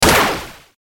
mech_fire_single.ogg